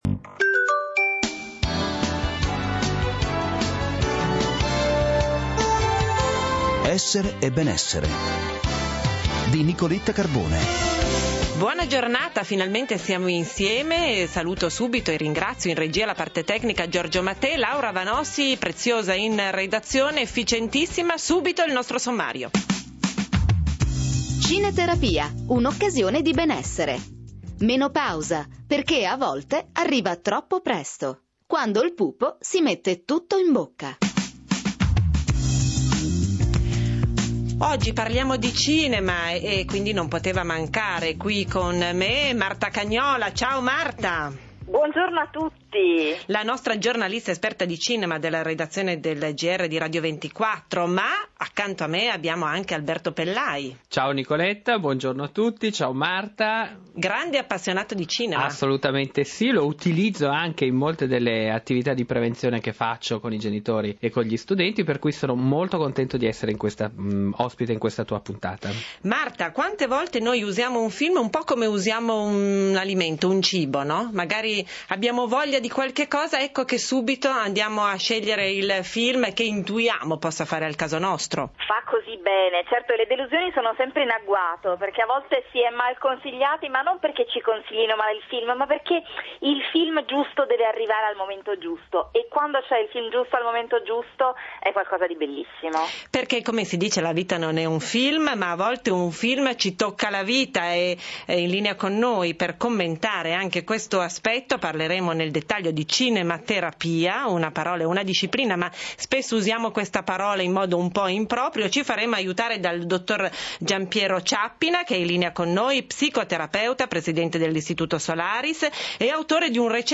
Il Sole 24 Ore – Radio 24, Intervista radiofonica, 7 marzo 2008